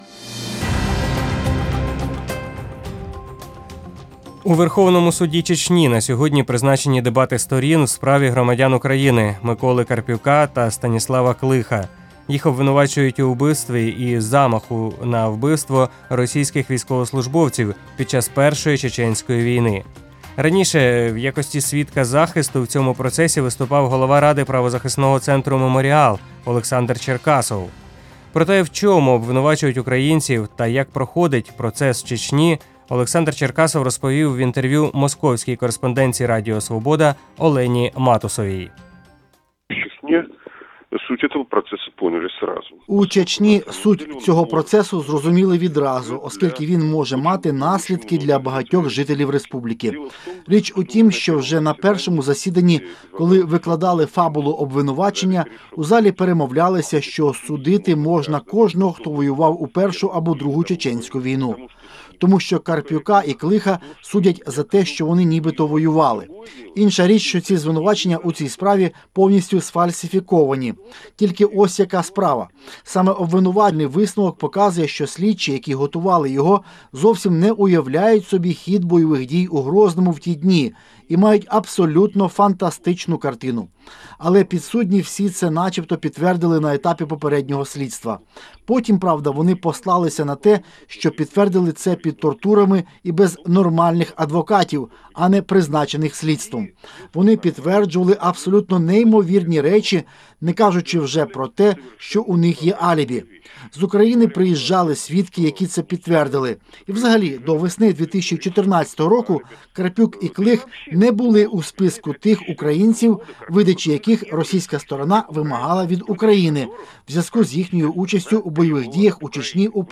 відео Радіо Свобода